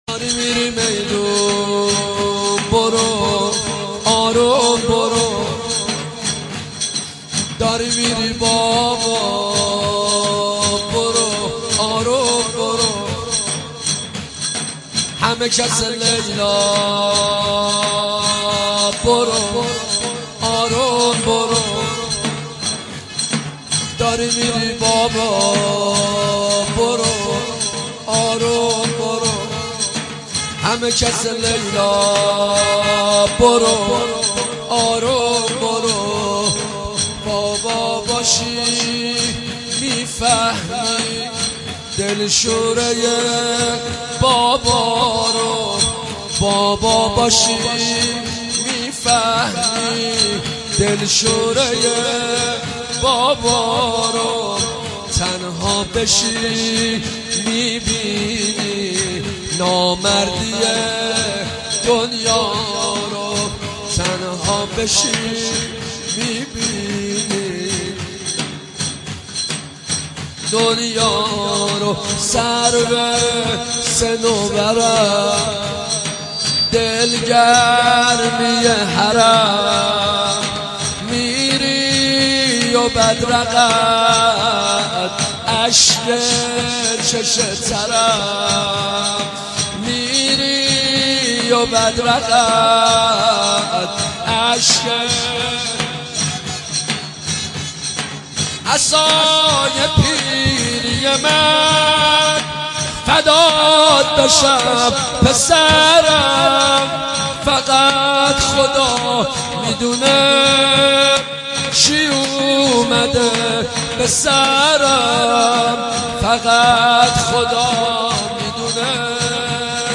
نوحه جديد
مداحی صوتی
تک طبل